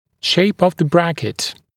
[ʃeɪp əv ðə ‘brækɪt][шэйп ов зэ ‘брэкит]форма брекета